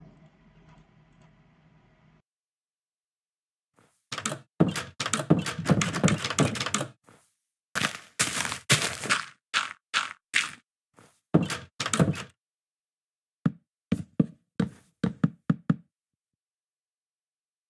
Just toggle HRTF in the sound options and hear the difference - there is a difference in the sound when you click on the UI in 1.20.1, but no difference in 1.20.2.{*}{*}
HRTF turned on.m4a